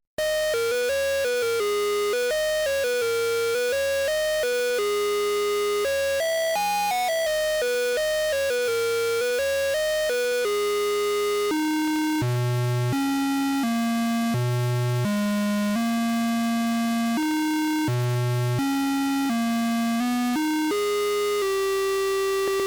Damn, that sounds gorgeous.